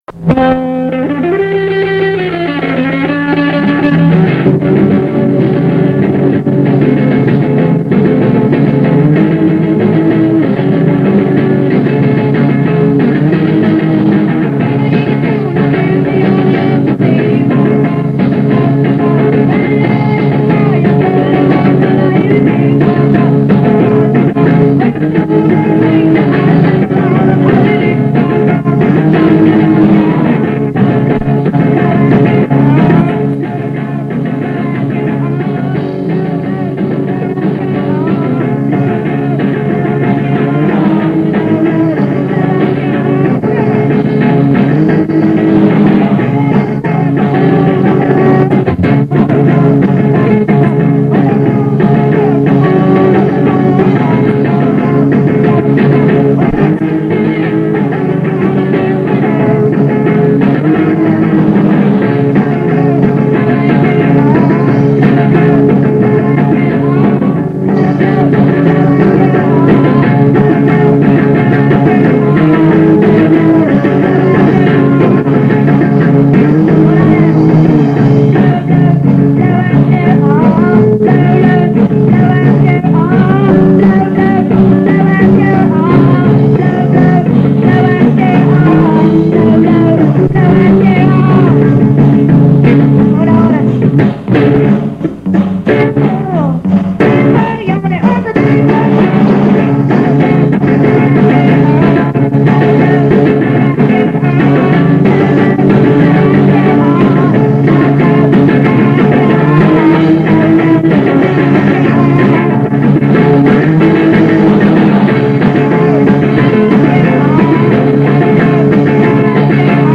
Trummor
Gitarr
Sång